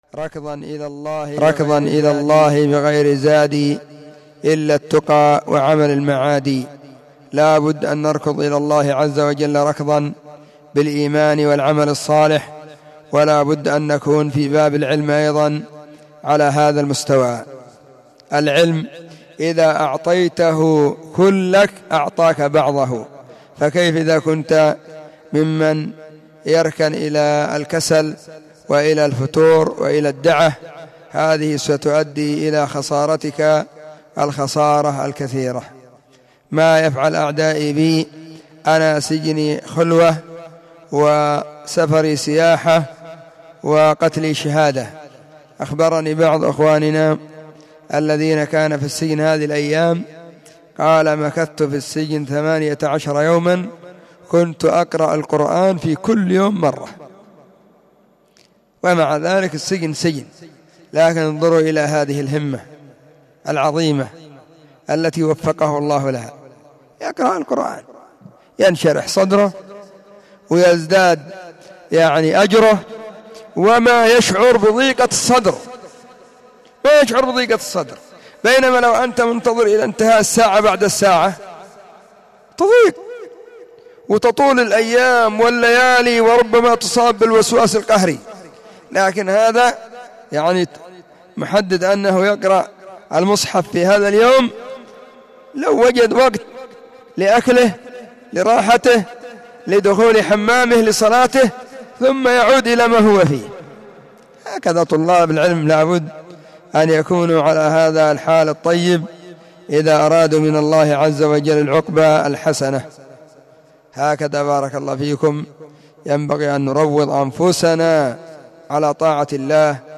💢نصيحة قيمة بعنوان💢ركضا إلى الله بغير زاد*
📢 مسجد الصحابة بالغيضة, المهرة، اليمن حرسها الله.